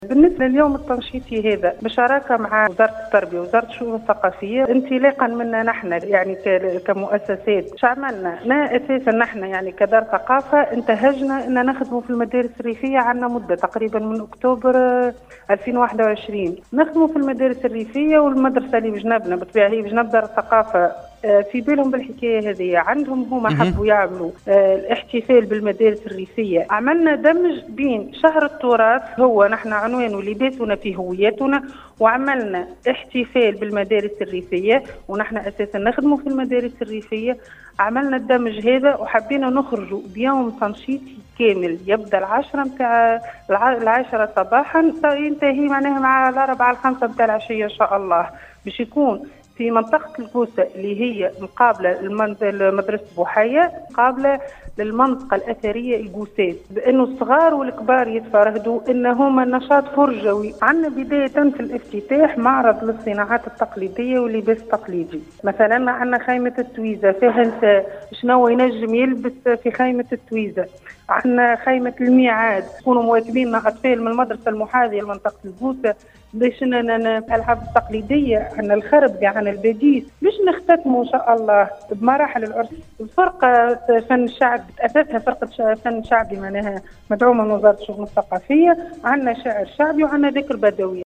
أثناء تدخّلها صباح اليوم ببرنامج القصرين و أحوالها بإذاعة السيليوم أف أم